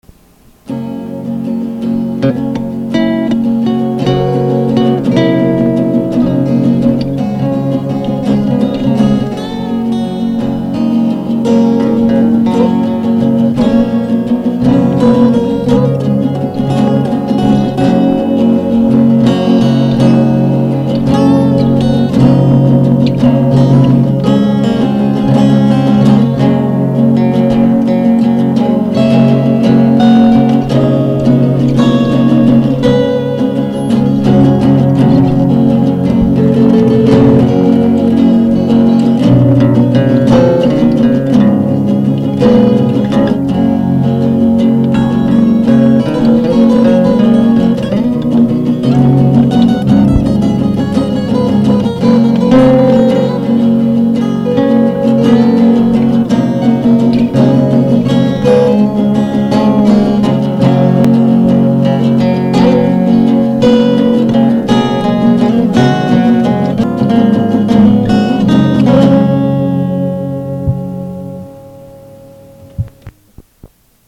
fourtrack instrumental